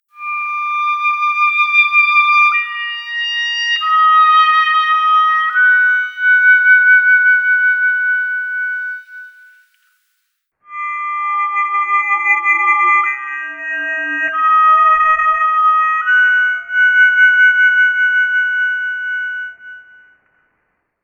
Puis la même mélodie mais sous échantillonnée à F_e= 4410 Hz, ce qui fait apparaître artificiellement des fréquences supplémentaires qui modifient la mélodie perçue.
On entend en premier une mélodie de hautbois échantillonnée à F_e= 44100 Hz qui est perçue telle que jouée par le musicien,